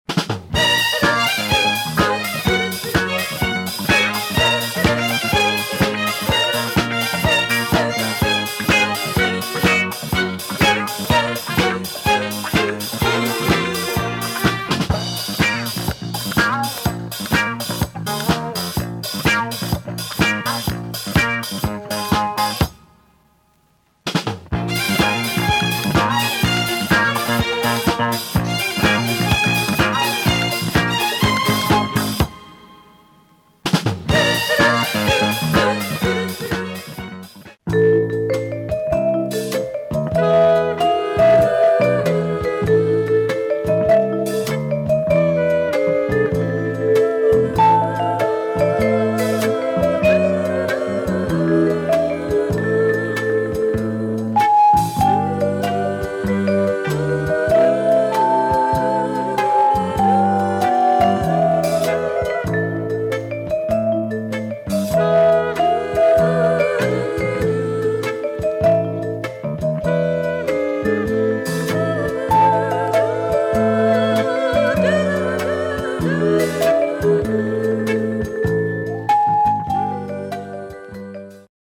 Surprising French soundtrack